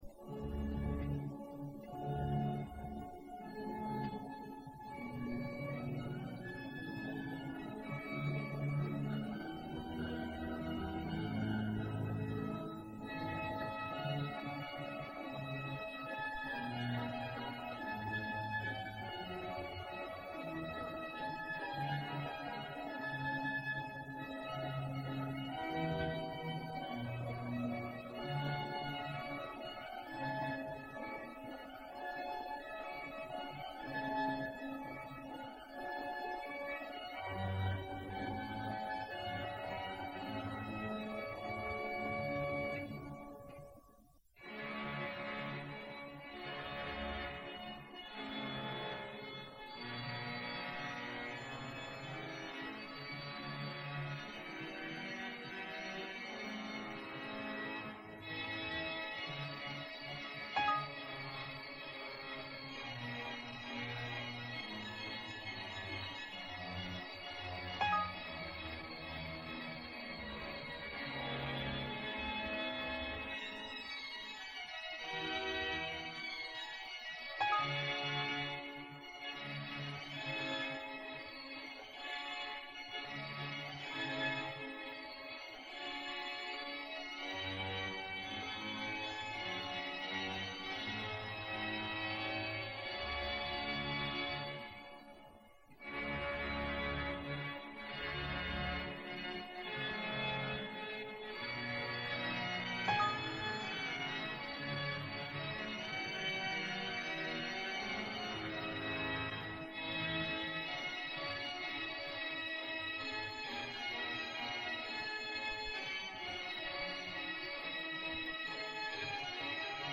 Los primeros conciertos de órgano
Algunos fragmentos audio del concierto de ICADE, pero de una calidad regular, según los medios técnicos de aquella época.